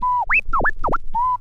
Scratch Steveland 2.wav